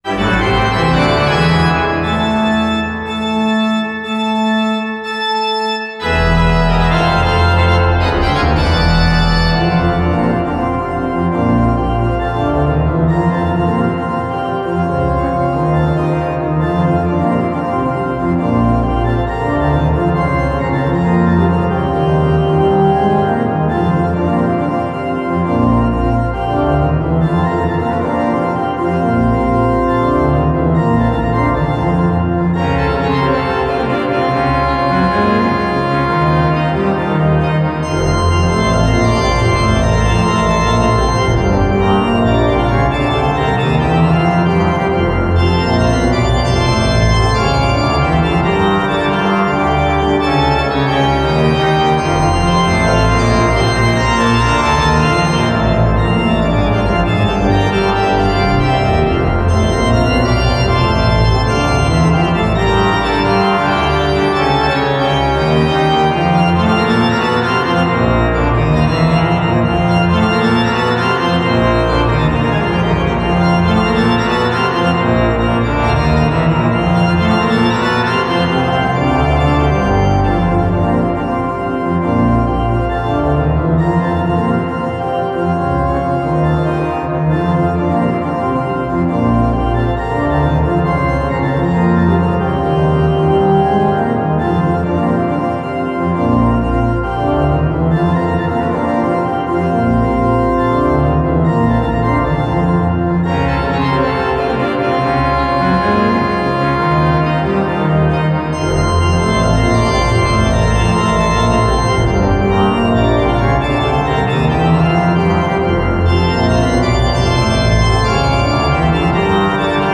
pipe_organ